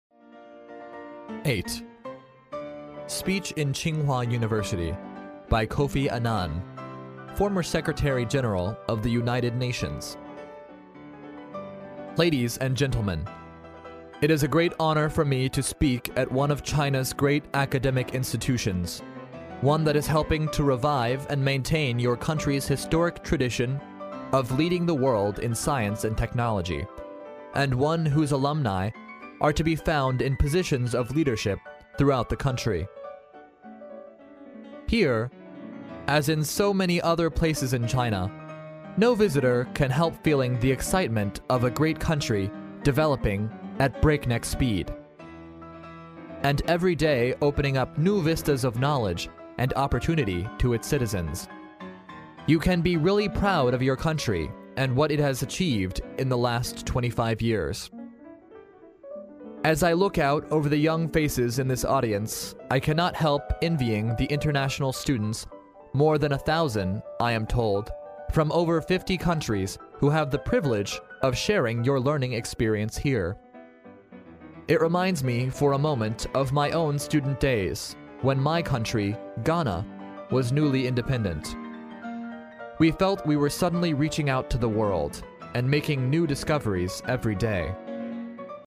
历史英雄名人演讲 第79期:前联合国秘书长安南在清华大学的演讲(1) 听力文件下载—在线英语听力室